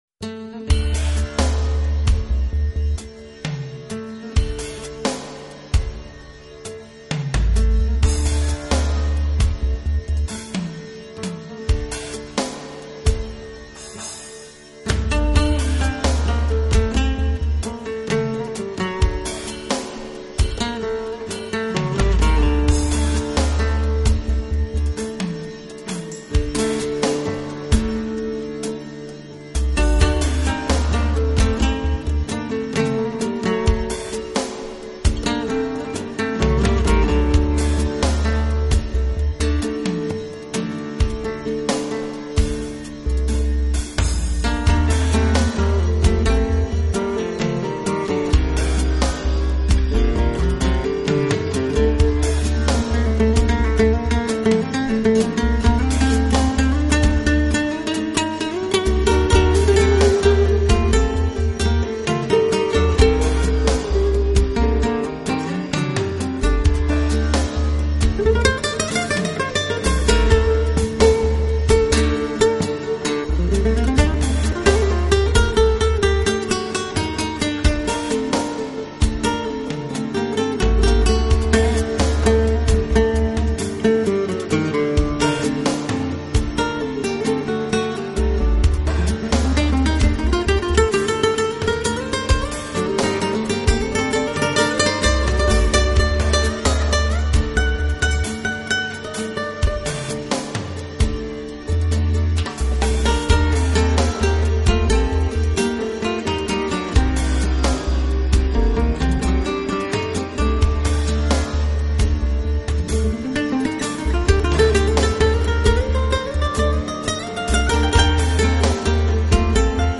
音乐风格：New Age / Instrumental / World Fusion